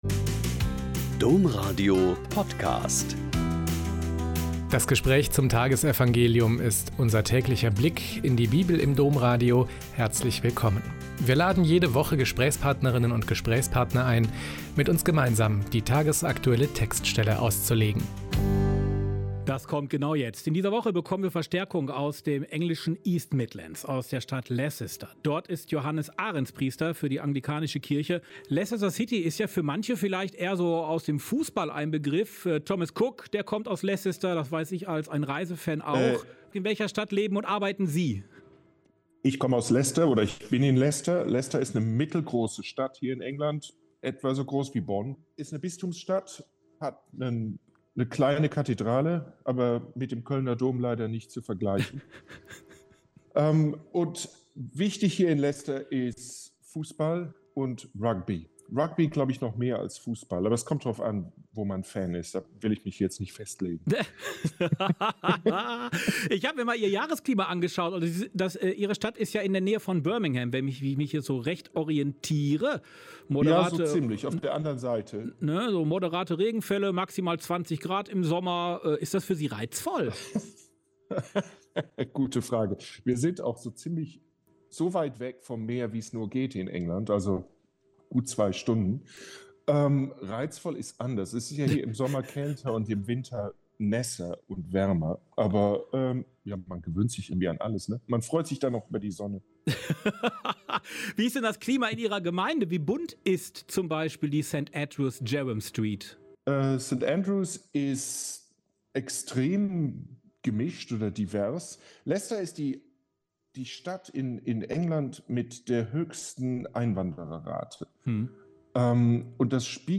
Mk 9,30-37 - Gespräch